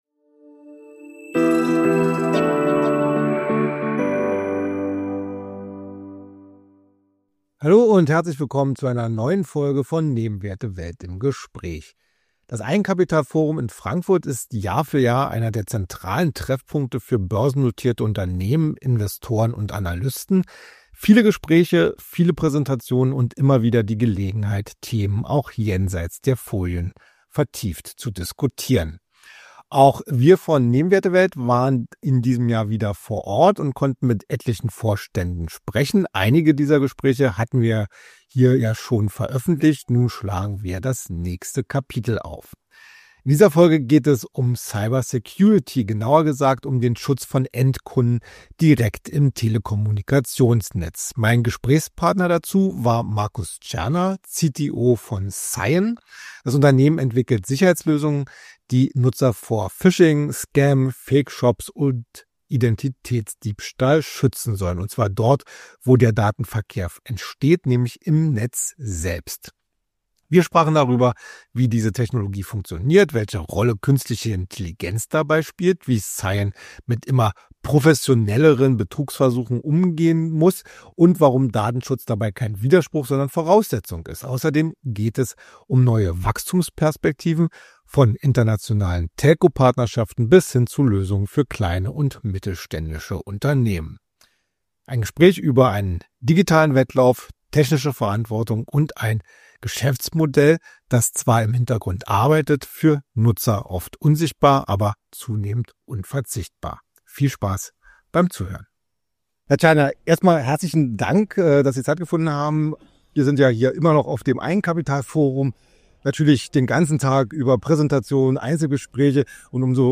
Aufgezeichnet wurde das Gespräch auf dem Eigenkapitalforum in Frankfurt.